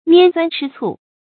拈酸吃醋 niān suān chī cù
拈酸吃醋发音
成语注音 ㄋㄧㄢ ㄙㄨㄢ ㄔㄧ ㄘㄨˋ